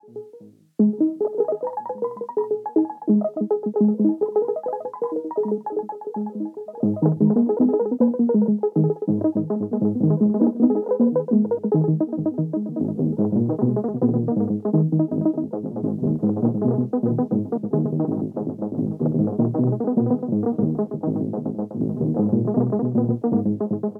Weird microcosm op loop.wav